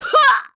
Seria-Vox_Damage_02_kr.wav